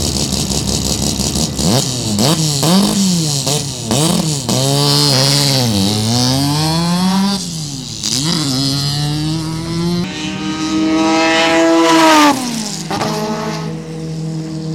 Rx3 13BPP video download Rx3 Coupe 13B Peripheral Port,
Motec Injected, Carbon Fibre inlet
(short) VIDEO download from Taupo 2003